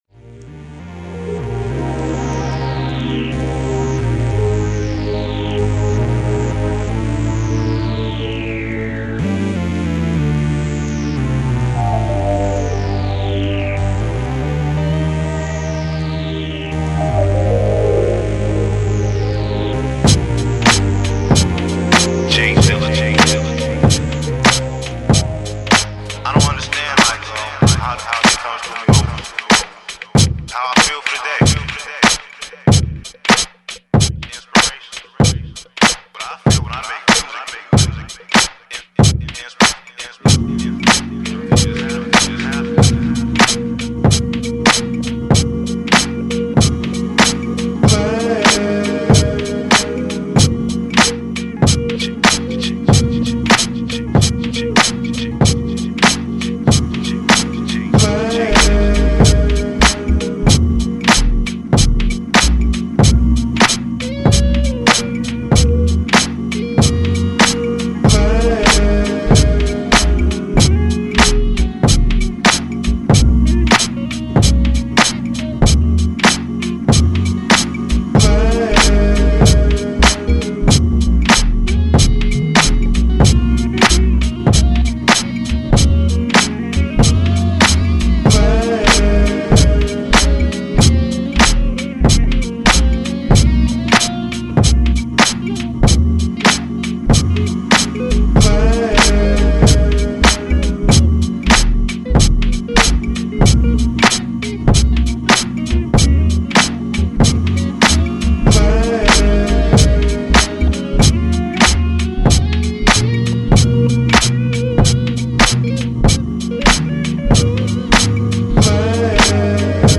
putting Soul on those beautiful beats